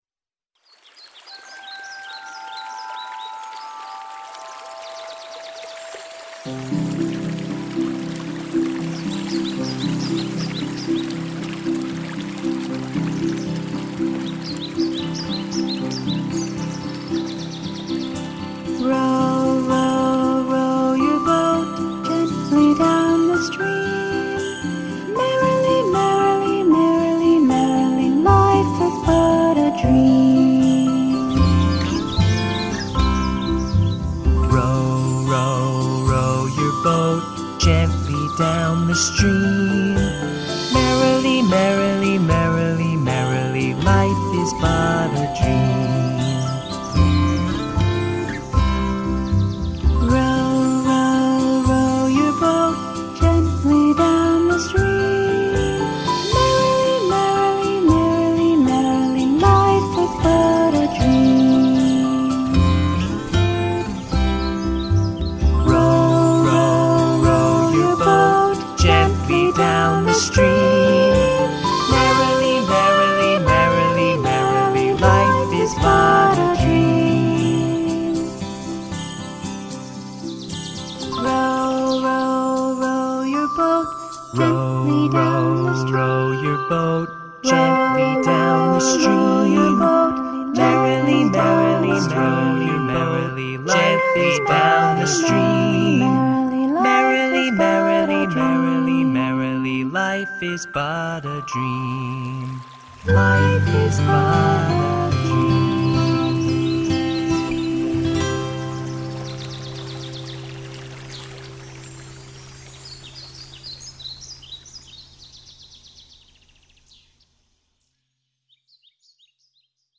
Group: Canon Members: Rota Musical composition in which two or more voices sing exactly the same melody (and may continue repeating it indefinitely), but with each voice beginning at different times so that different parts of the melody coincide in the different voices, but nevertheless fit harmoniously together. A round is a simple type of canon.